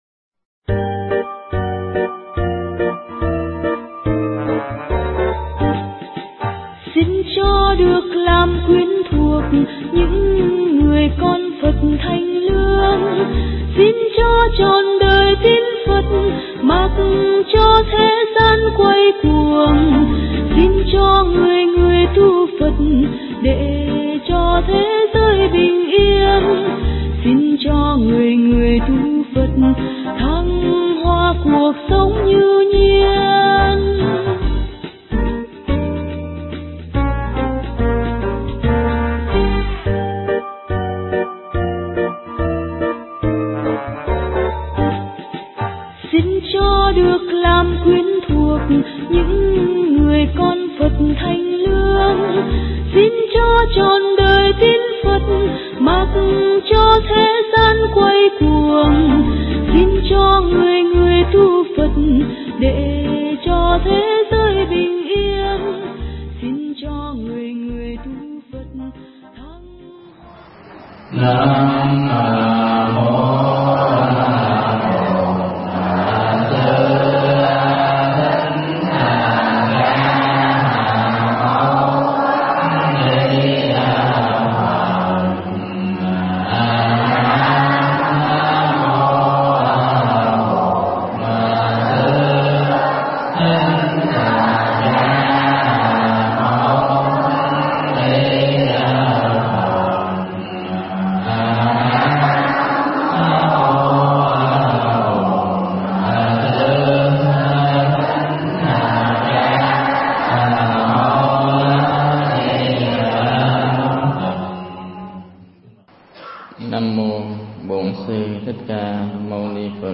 Nghe Mp3 thuyết pháp Mỗi người có 1 mái chùa
Mp3 Thuyết Giảng Mỗi người có 1 mái chùa